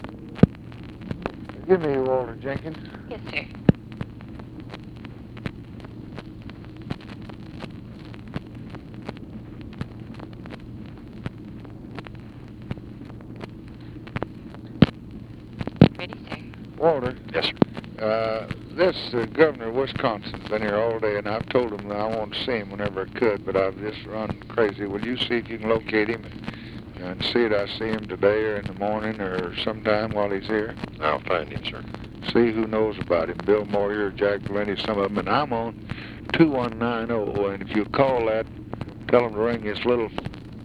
Conversation with WALTER JENKINS, March 10, 1964
Secret White House Tapes